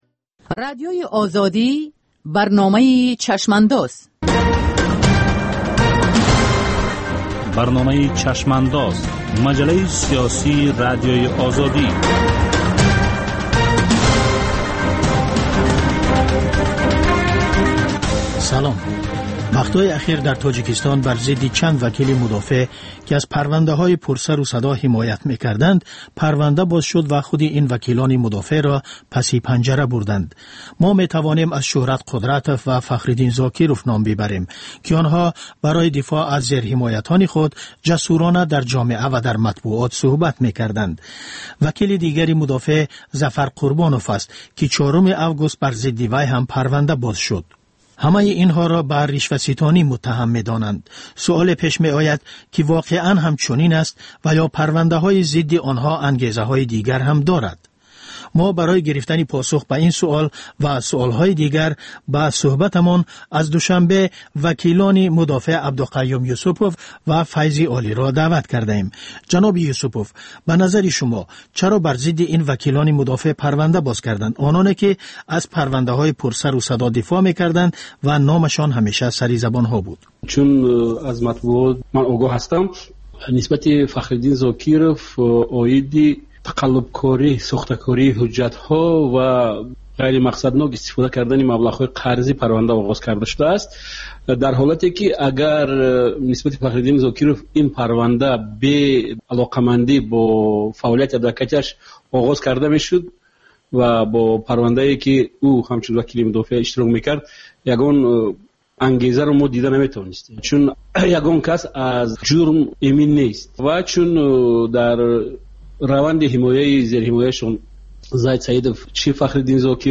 Баррасӣ ва таҳлили муҳимтарин рӯйдодҳои сиёсии рӯз дар маҷаллаи "Чашмандоз". Гуфтугӯ бо коршиносон, масъулини давлатӣ, намояндагони созмонҳои байналмилалӣ.